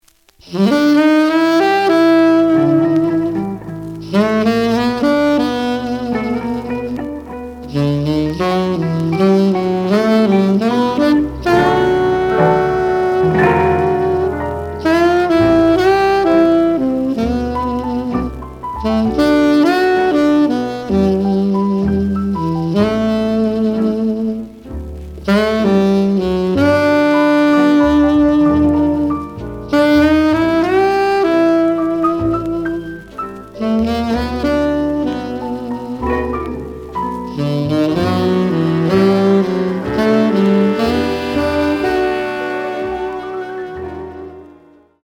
The audio sample is recorded from the actual item.
●Genre: Modern Jazz
Looks good, but slight noise on both sides.